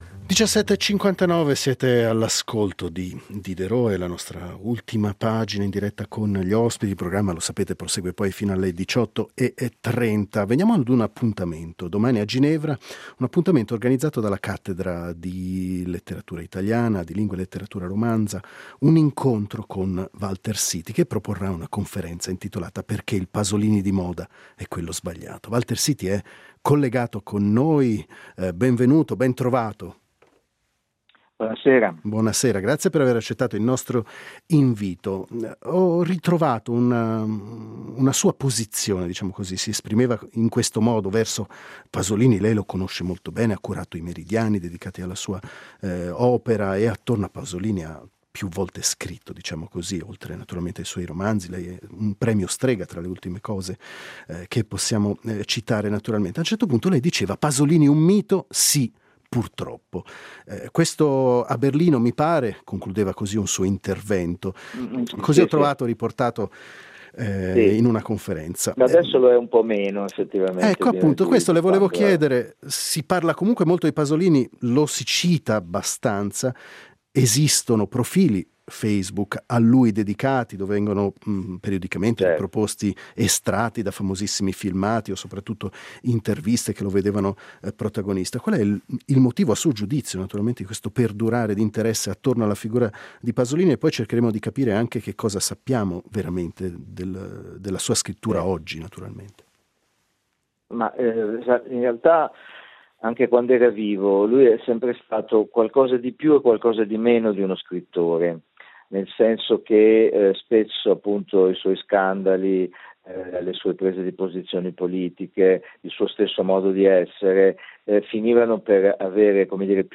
Una conferenza di Walter Siti a Ginevra.